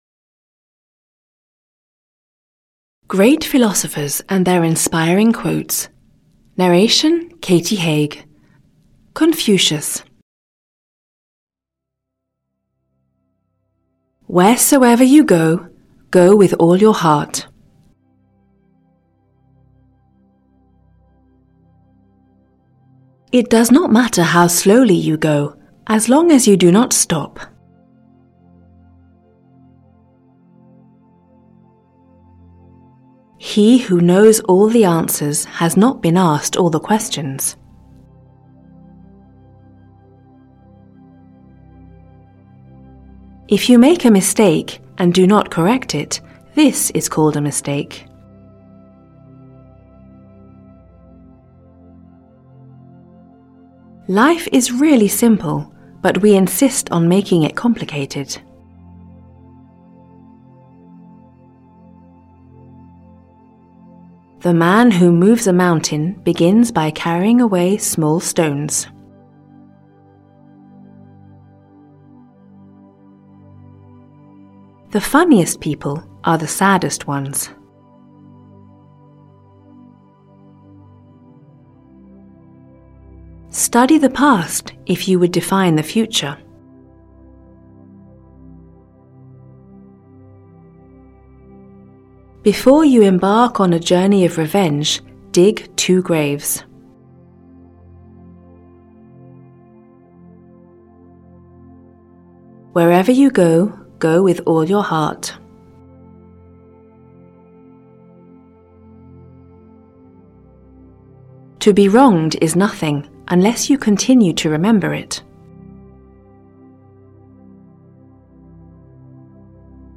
Audio kniha100 Quotes by Confucius: Great Philosophers & Their Inspiring Thoughts (EN)
Ukázka z knihy